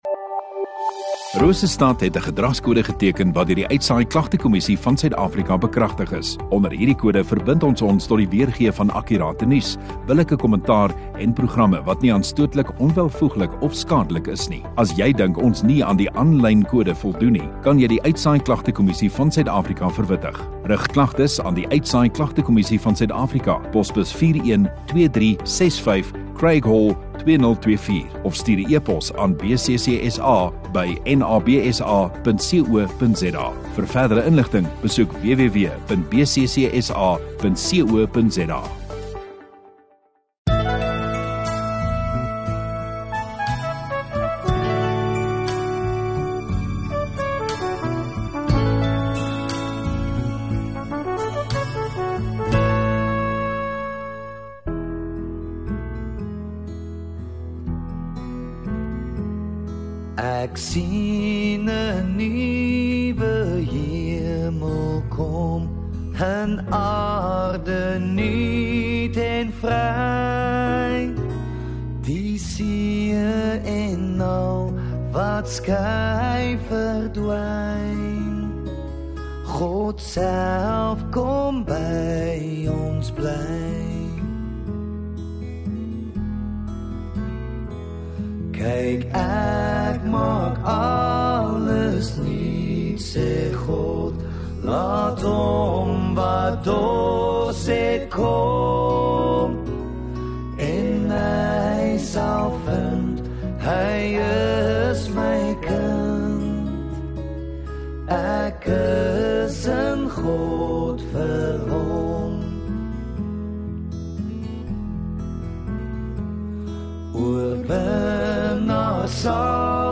29 May (Hemelvaartsdag) Donderdagaand Erediens